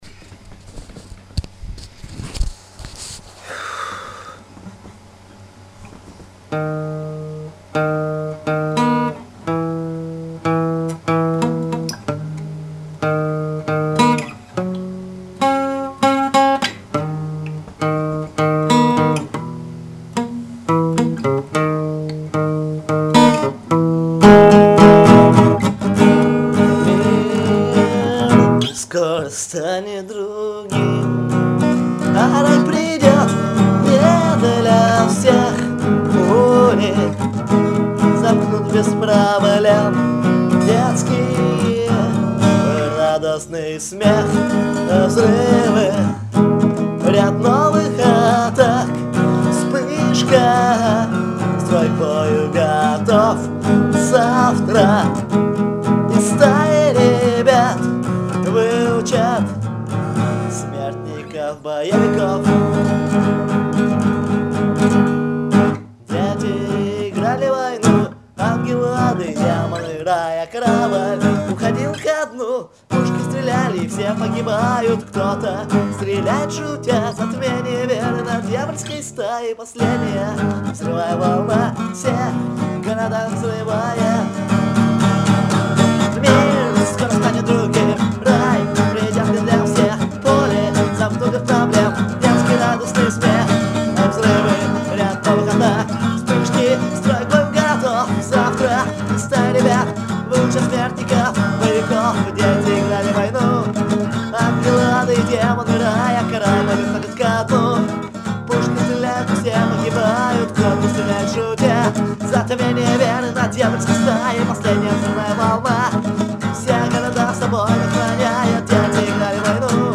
Категория: Акустика